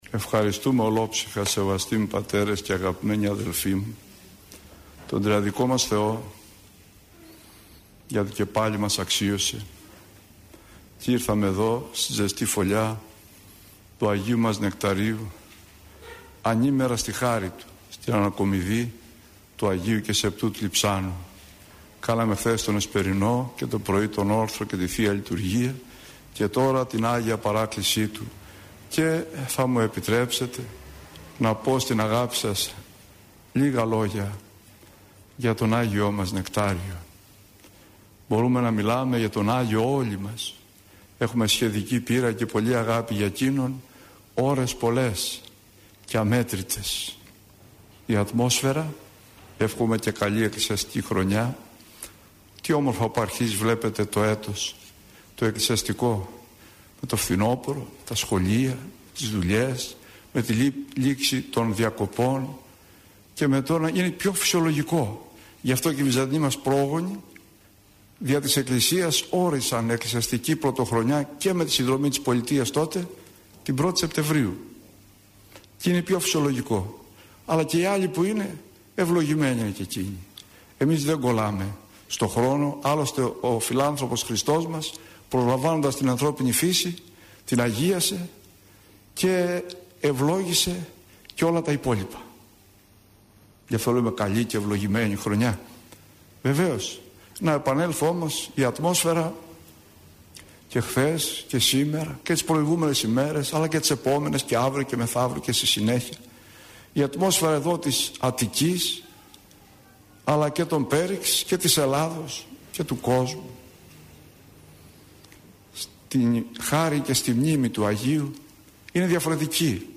ηχογραφημένη ομιλία
Η εν λόγω ομιλία αναμεταδόθηκε από τον ραδιοσταθμό της Πειραϊκής Εκκλησίας.